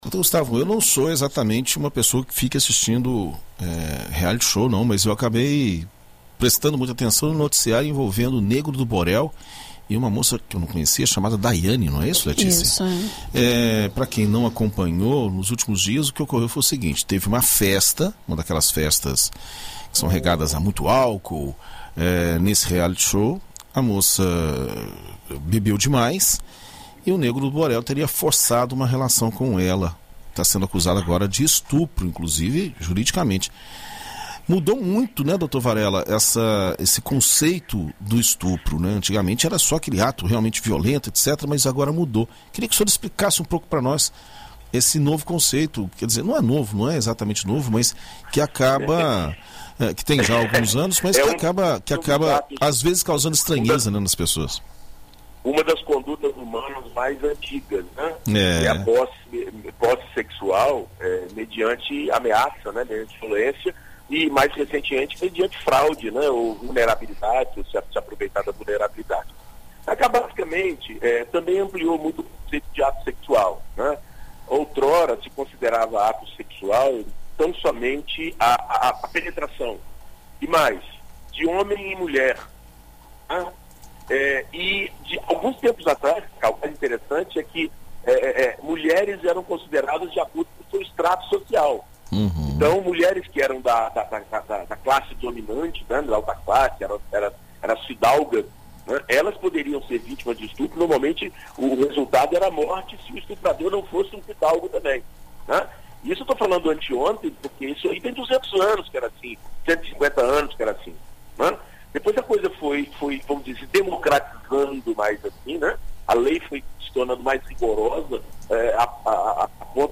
Na coluna Direito para Todos desta segunda-feira (27), na BandNews FM Espírito Santo